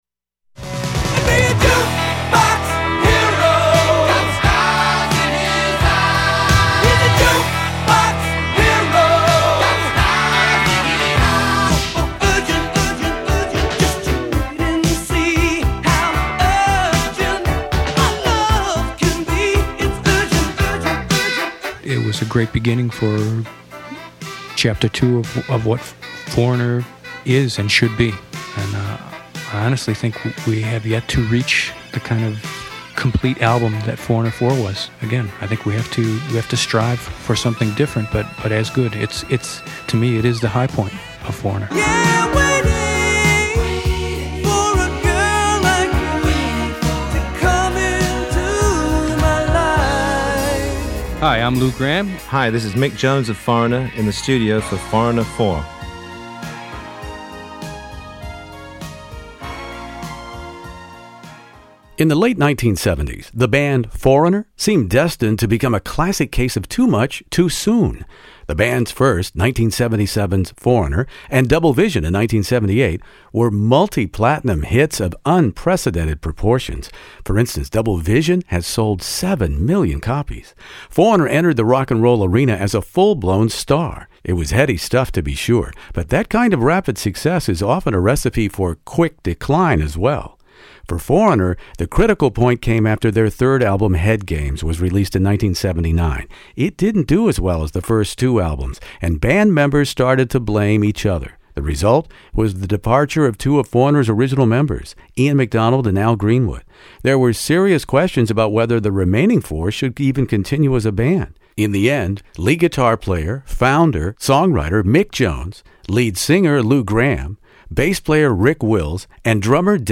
Foreigner 4 In the Studio interview with Mick Jones, Lou Gramm